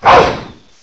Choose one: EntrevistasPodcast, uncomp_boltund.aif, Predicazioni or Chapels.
uncomp_boltund.aif